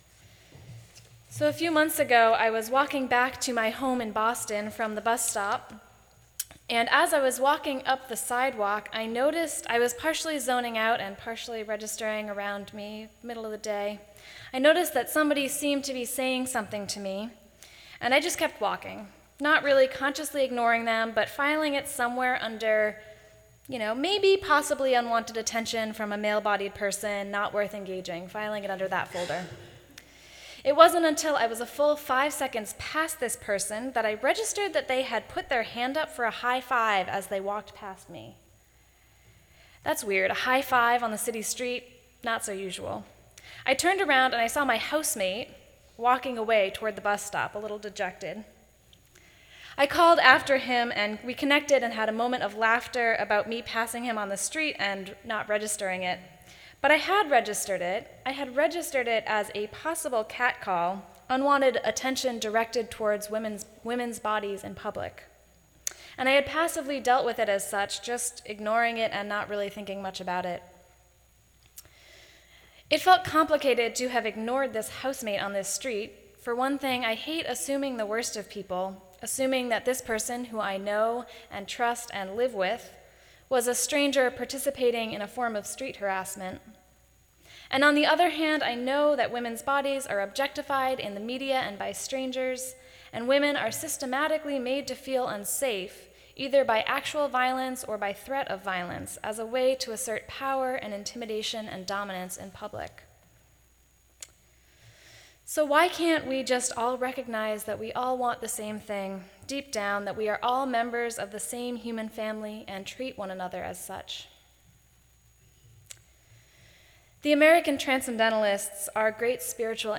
The podcast is of the reading and sermon.
sermon11_9_14.mp3